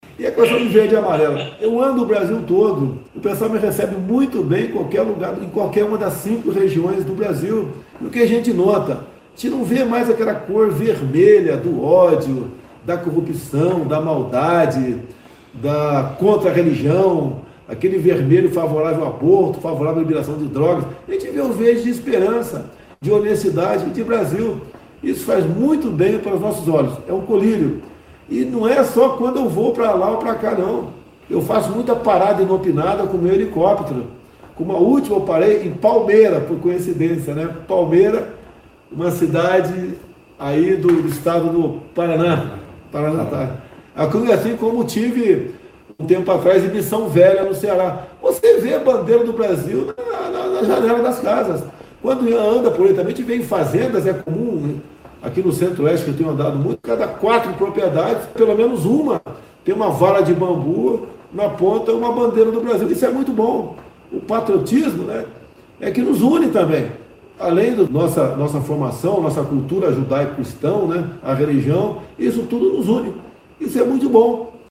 Em entrevista para rádio, Bolsonaro fala da visita em Palmeira
Caso não consiga ouvir neste formato, Clique aqui e ouça a fala do Presidente Jair Bolsonaro.